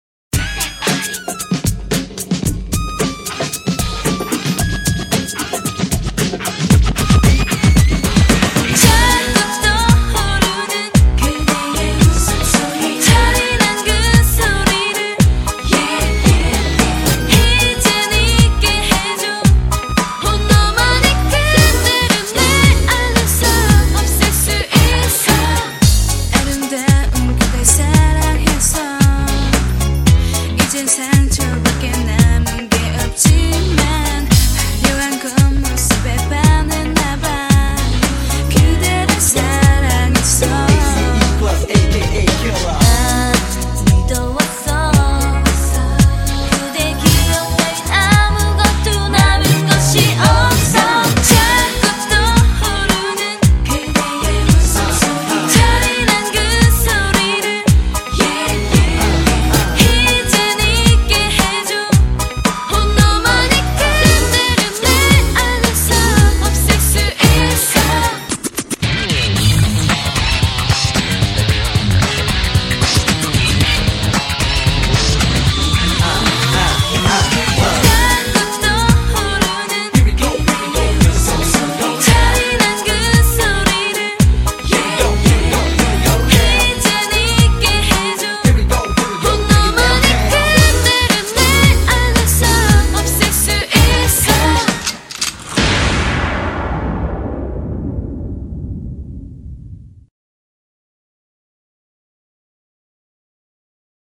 BPM113--1
Audio QualityPerfect (High Quality)